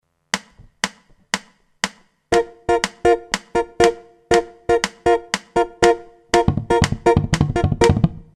i pattern ritmici più diffusi sono la Clave di Son